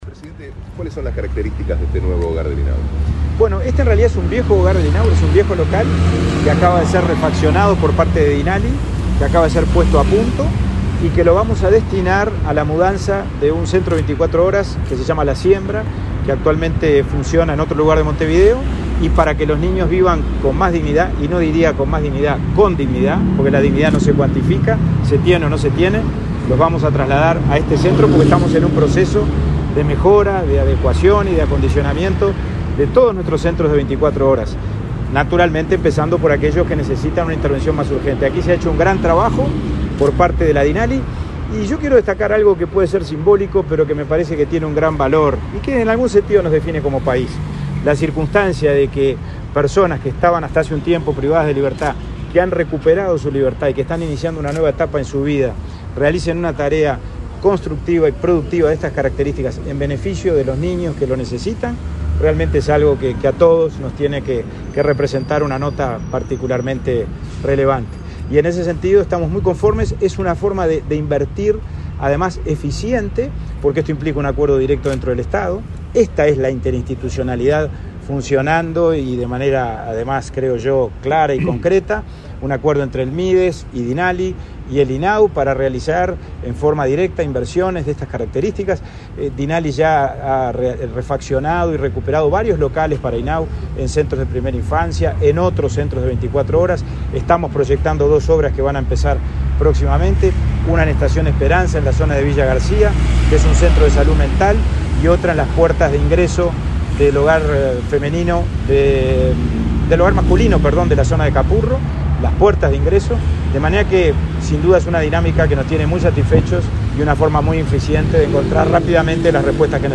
Declaraciones del presidente del INAU, Pablo Abdala, a la prensa
Tras el evento, el presidente del instituto efectuó declaraciones a la prensa.
abdala prensa.mp3